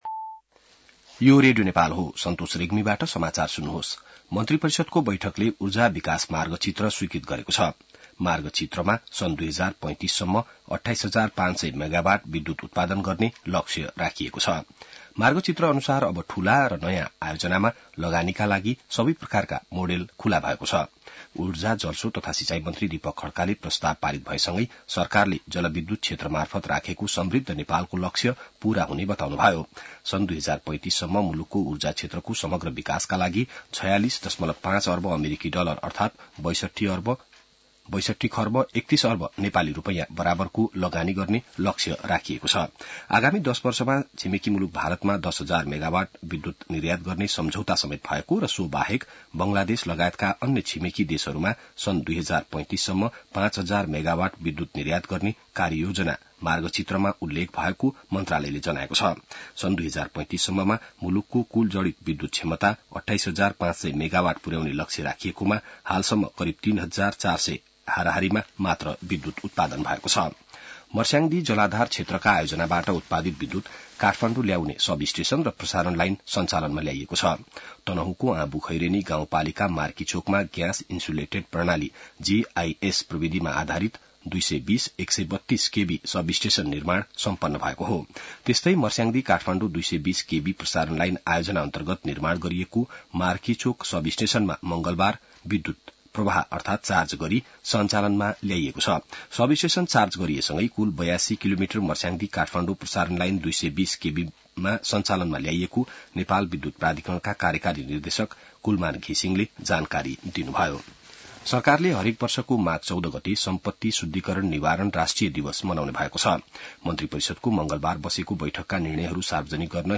बिहान ६ बजेको नेपाली समाचार : १९ पुष , २०८१